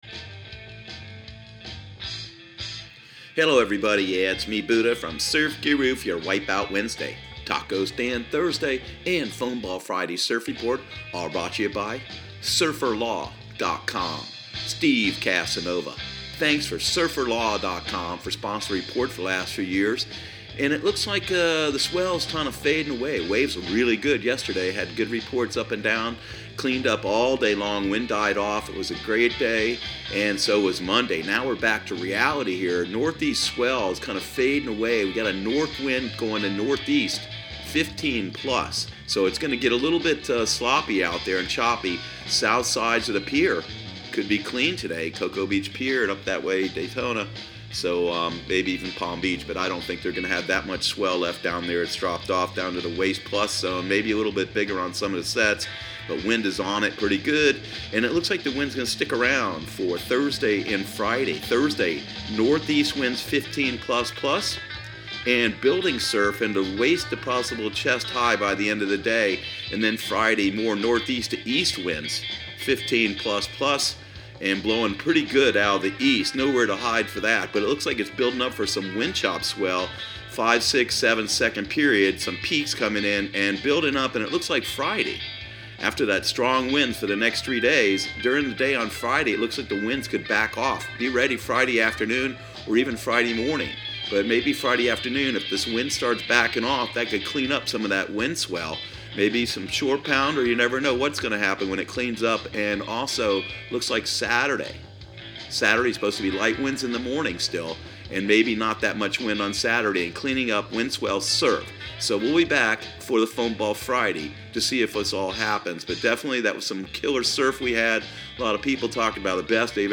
Surf Guru Surf Report and Forecast 01/30/2019 Audio surf report and surf forecast on January 30 for Central Florida and the Southeast.